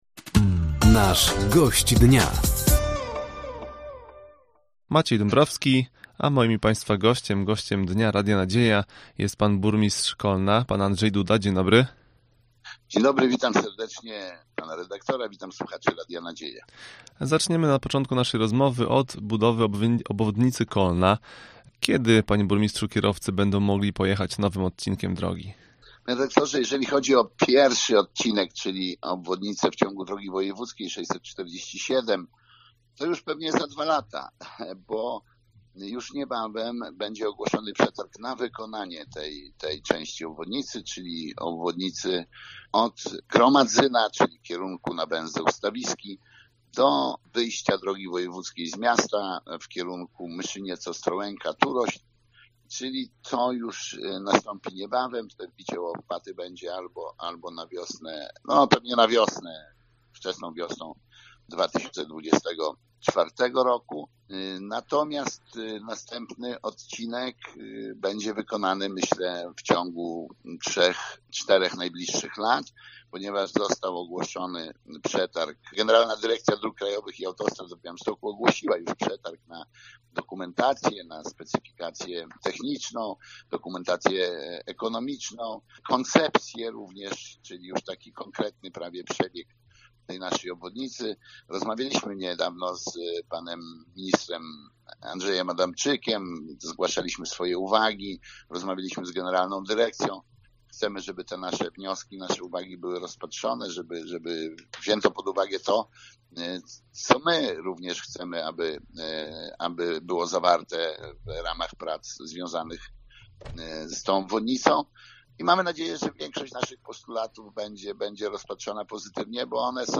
Gościem Dnia Radia Nadzieja był Andrzej Duda, Burmistrz Kolna. Tematem rozmowy były inwestycje drogowe, lądowisko szpitalne i plany rozwojowe miasta.